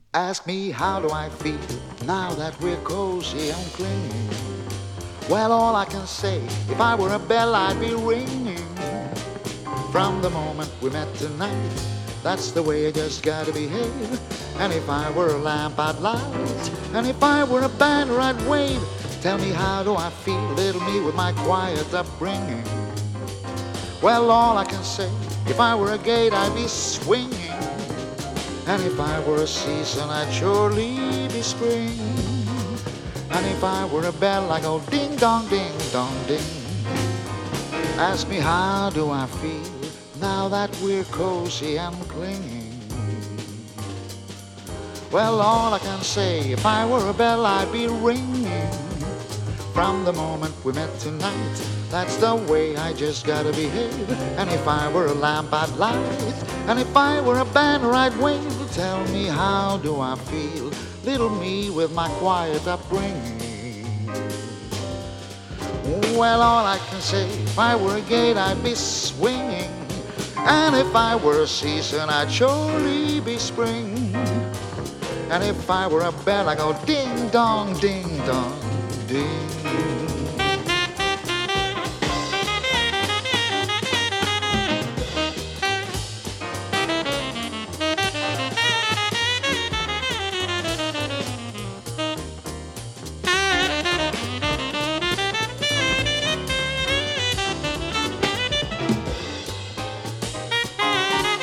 スウェーデンのボーカリストによる最高傑作。
ジャジーなアレンジが素晴らしい大人気盤です！